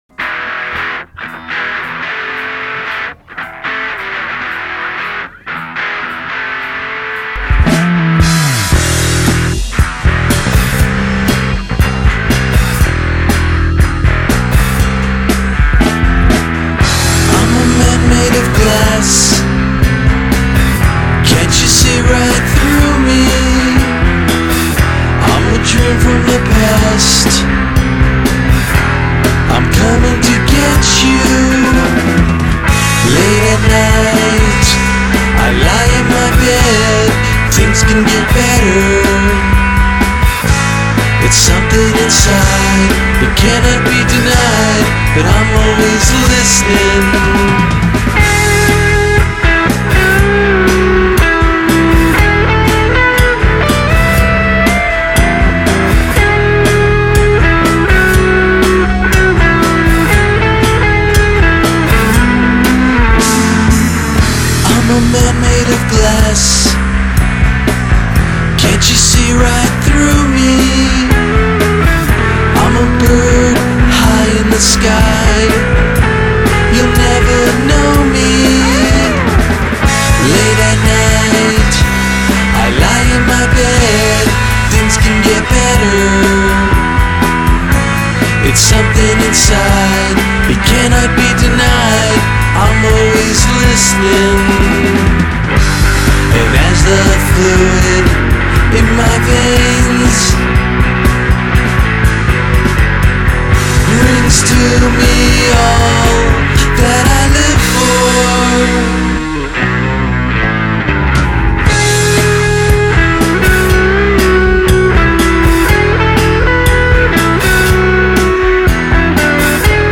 Drumloops recorded by real drummers on real drumkits.